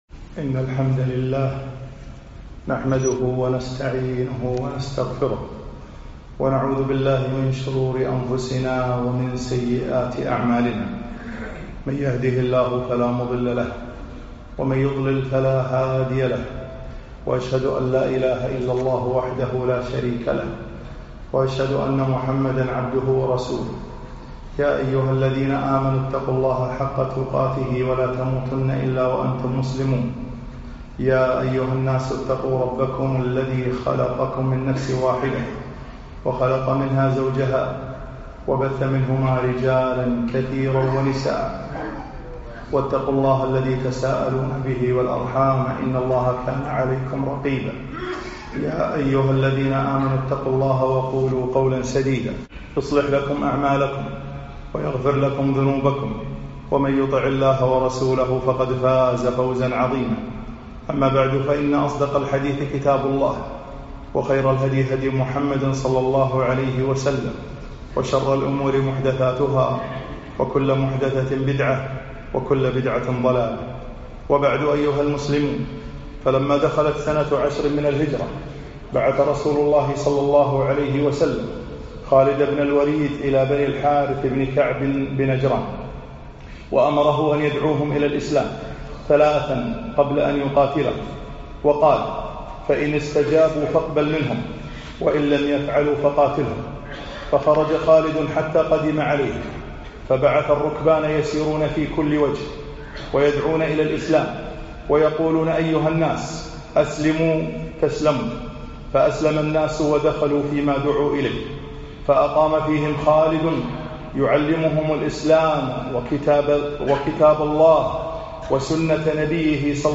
خطب السيرة النبوية 28